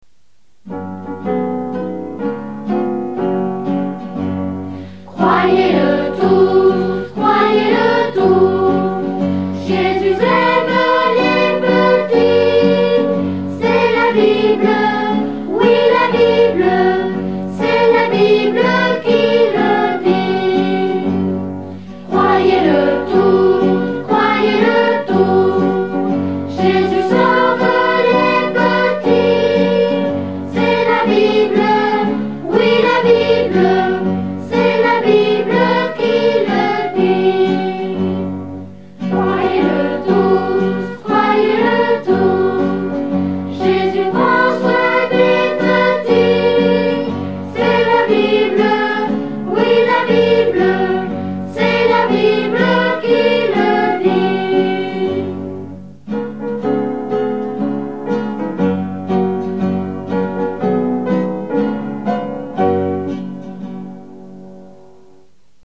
Suite aux histoires, quelques cantiques vont paraître.